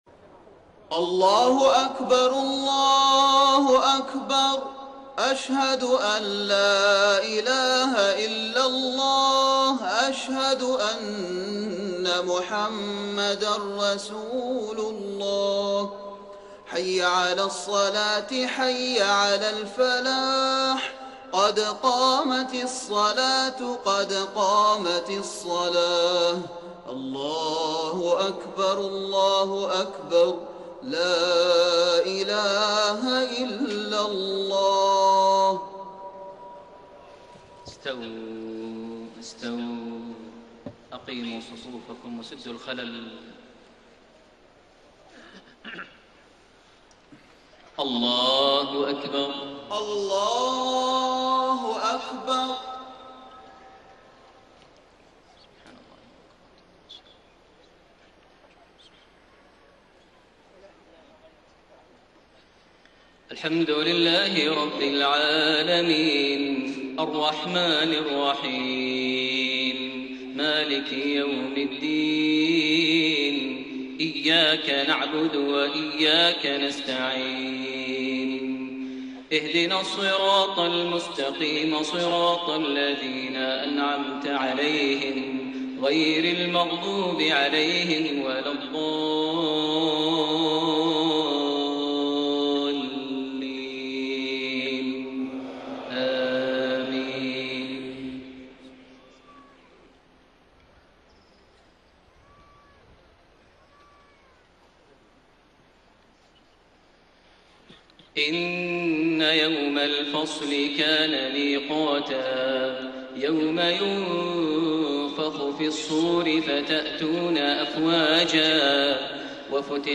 صلاة المغرب 30 جمادى الآخرة 1433هـ خواتيم سورة النبأ 17-40 > 1433 هـ > الفروض - تلاوات ماهر المعيقلي